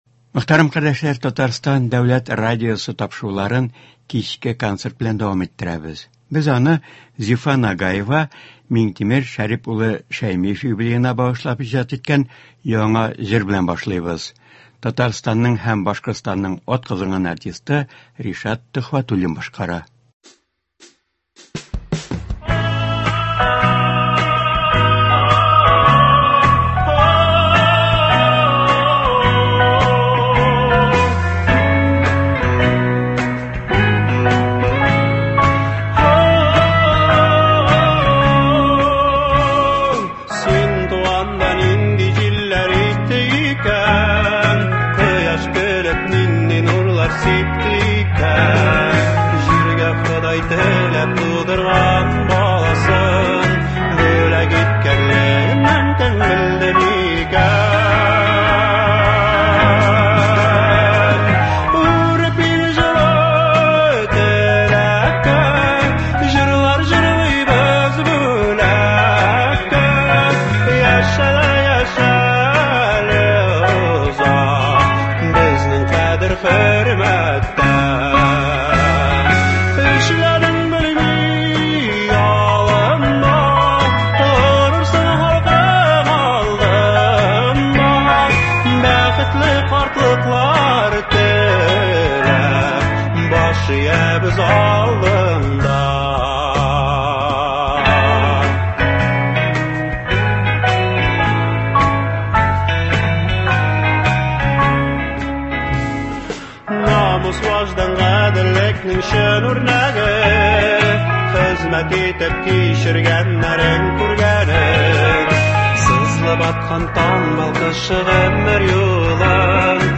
Галимҗан Латыйп шигырьләренә җырлар.